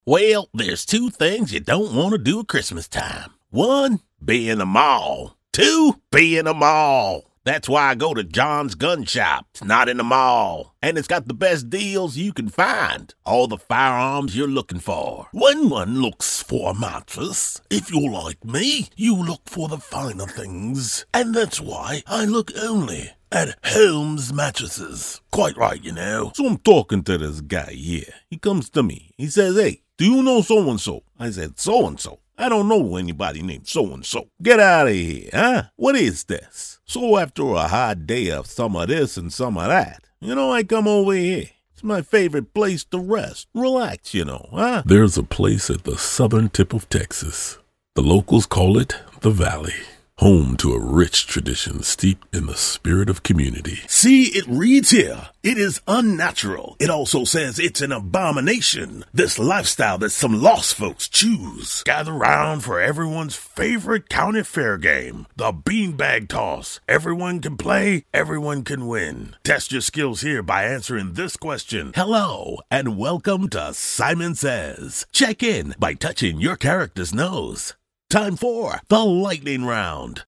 Male
English (North American)
Adult (30-50)
THE ABILITY TO DO A VARIETY OF STYLES AND TYPES.
Character / Cartoon